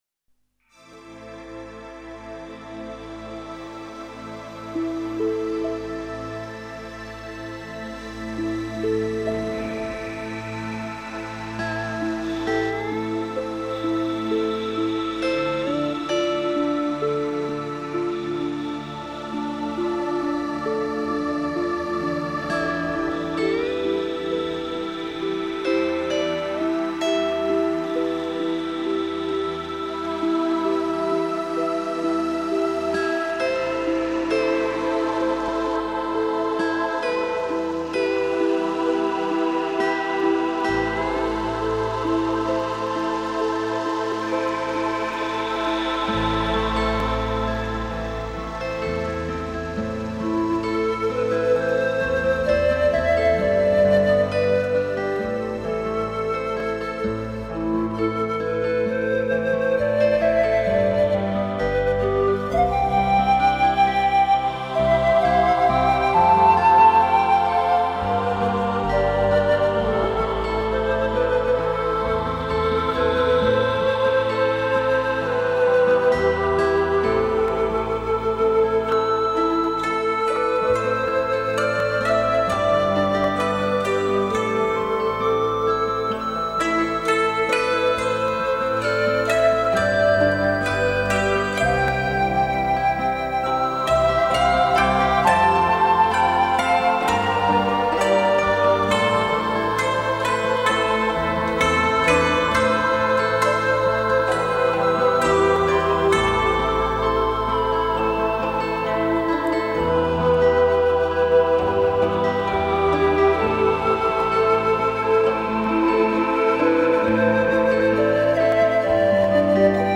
Музыка для медитаций